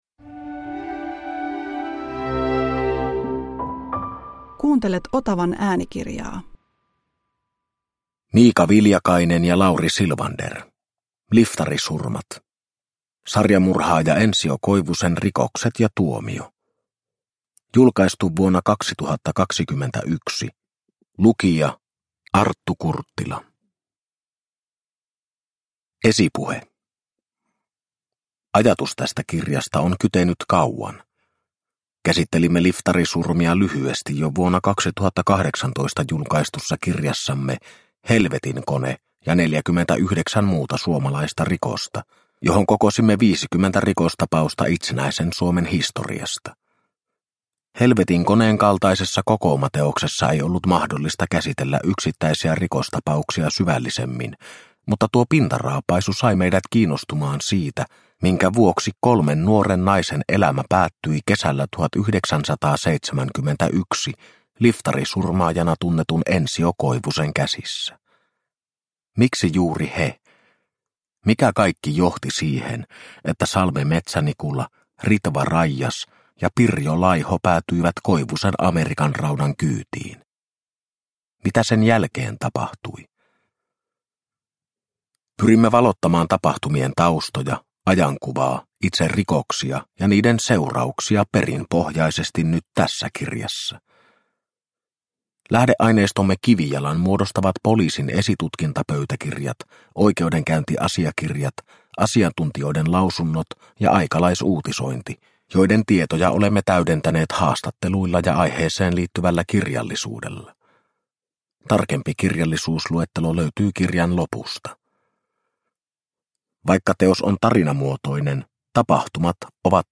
Liftarisurmat – Ljudbok – Laddas ner